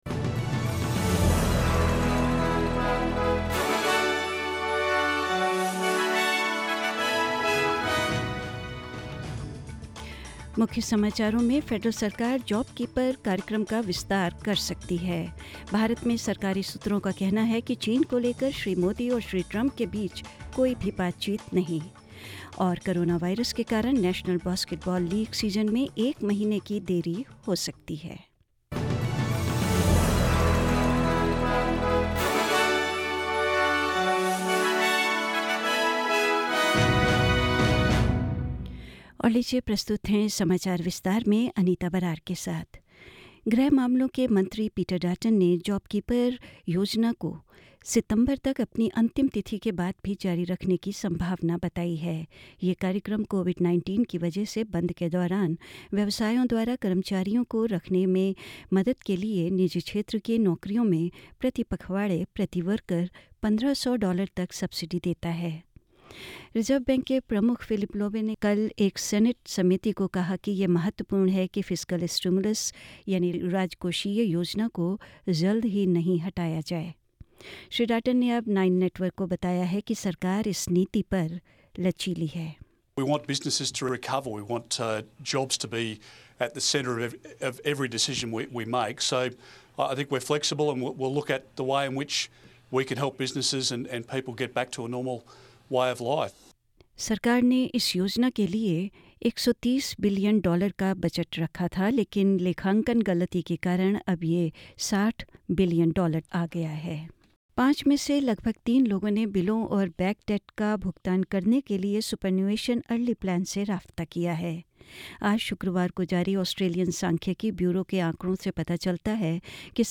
Hindi News 29th May 2020